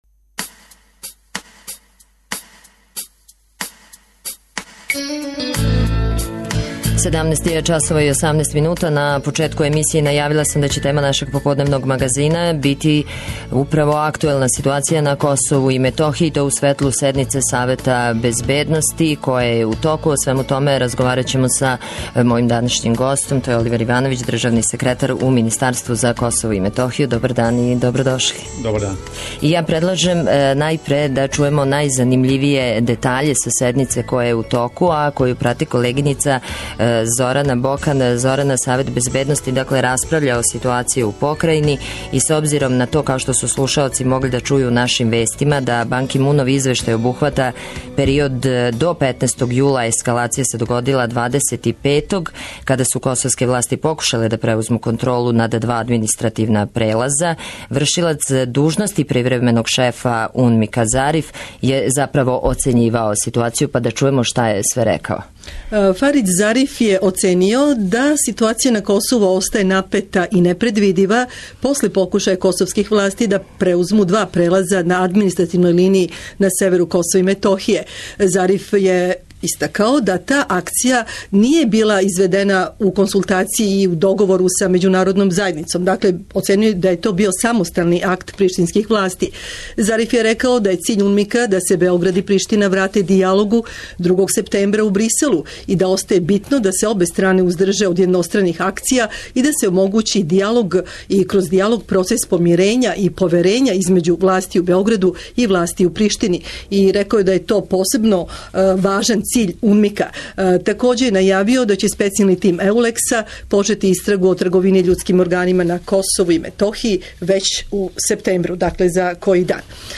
Тема емисије је актуелна ситуација на Косову и Метохији у светлу седнице Савета безбедности Уједињених нација. Гост емисије је Оливер Ивановић, државни секретар у министарству за Косово и Метохију.